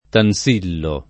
[ tan S& llo ]